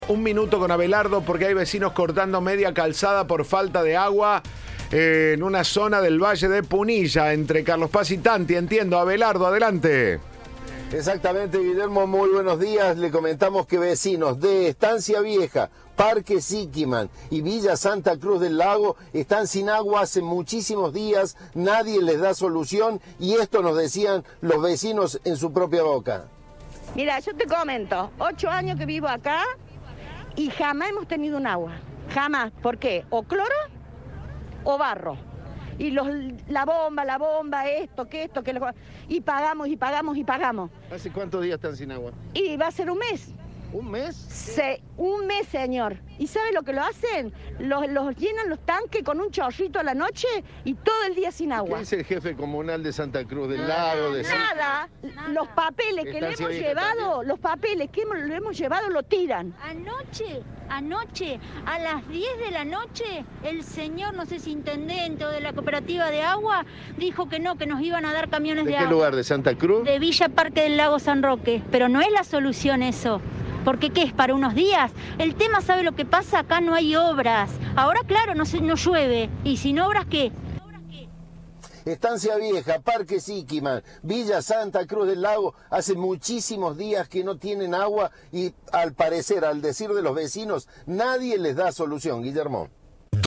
En diálogo con Cadena 3, una mujer que estaba presente en la manifestación expresó: "Hace 8 años que vivo acá y jamás hemos tenido agua. Si no tiene cloro, tiene barro".
"El jefe comunal no dice nada, los papeles que hemos llevado los tiran", expresó otra de las mujeres presentes y añadió: "El tema es que no hay obras".